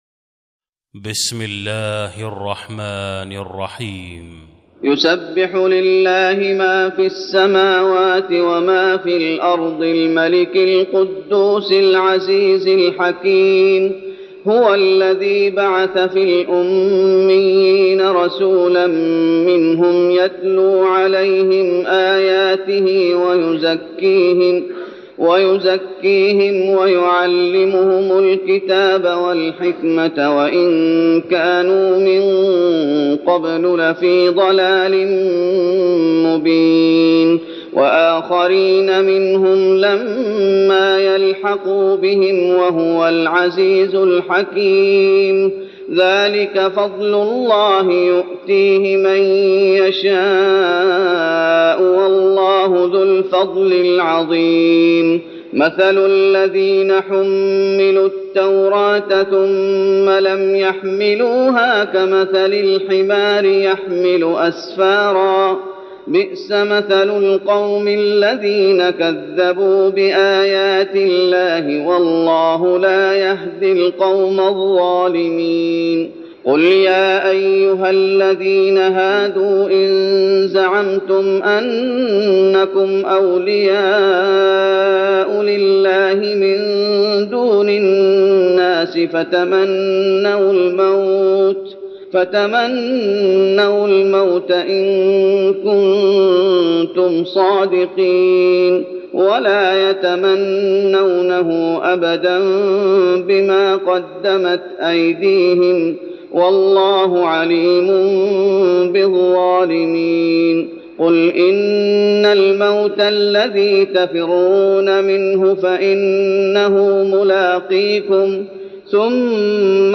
تراويح رمضان 1412هـ من سورة الجمعة Taraweeh Ramadan 1412H from Surah Al-Jumu'a > تراويح الشيخ محمد أيوب بالنبوي 1412 🕌 > التراويح - تلاوات الحرمين